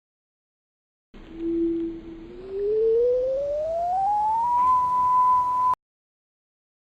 whistle
60557-whistle.mp3